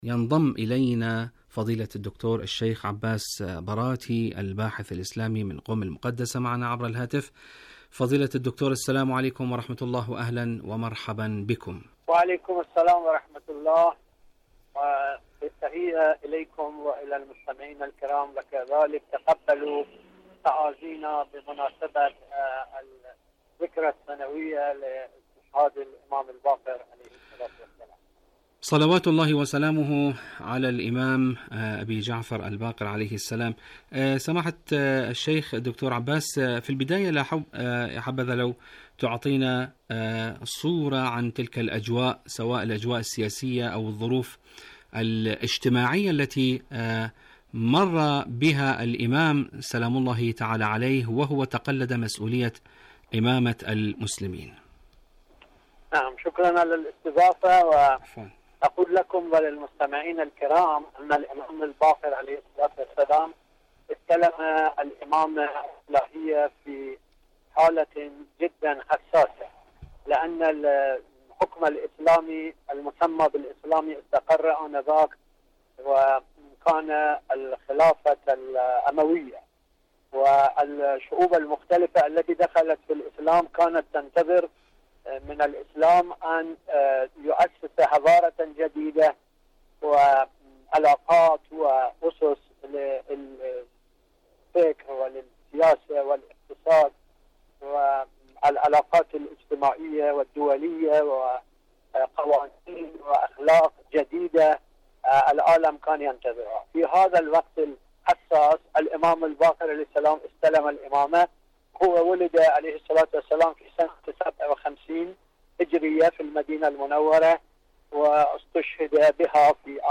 إذاعة طهران العربية مقابلات إذاعية برنامج باقر العلم